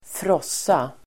Uttal: [²fr'ås:a]